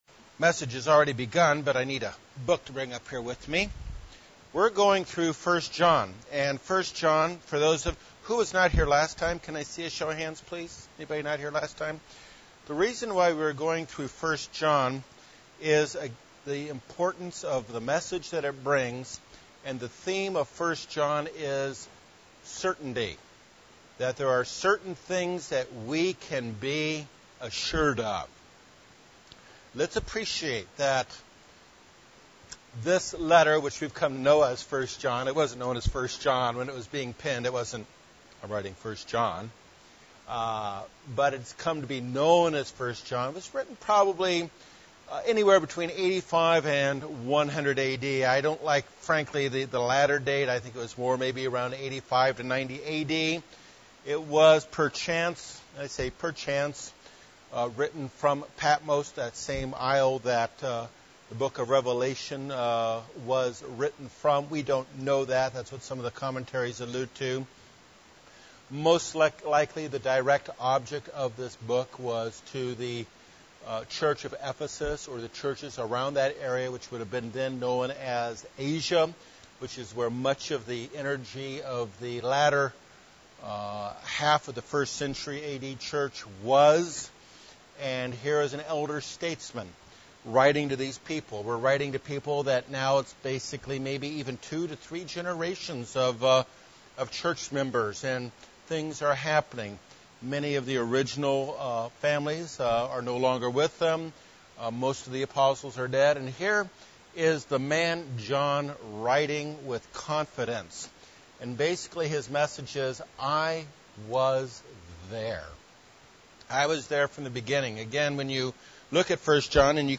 Continuing Bible study on the book of 1 John. 1 John is an important message about certainly and what we can be assured of.